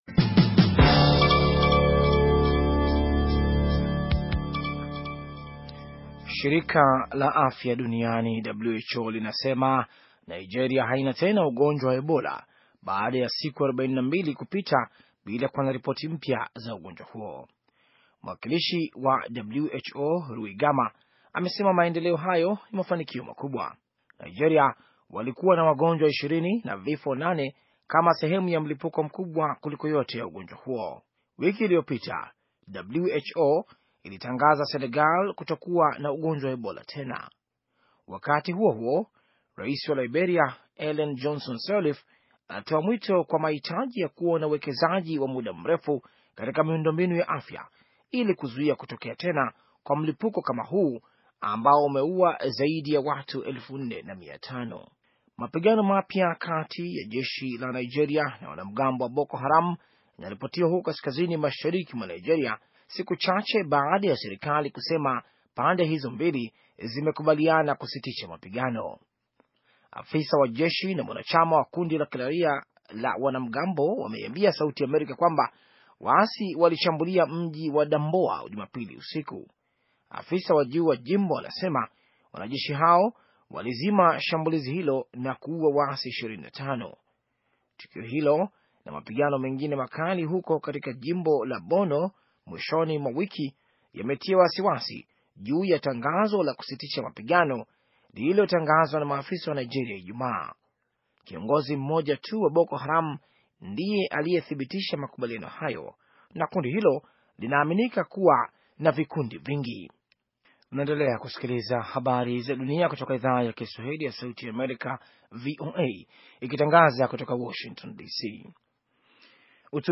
Taarifa ya habari - 5:56